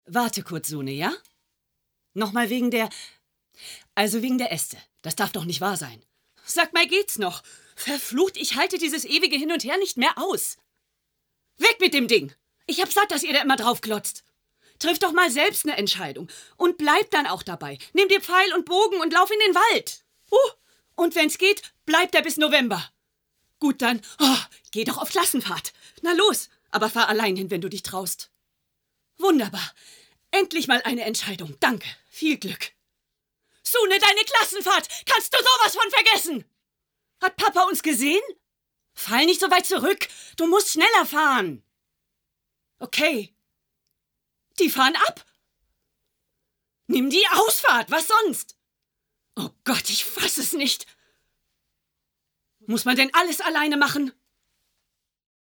Norwegische Komödie_lebendig, aufgebracht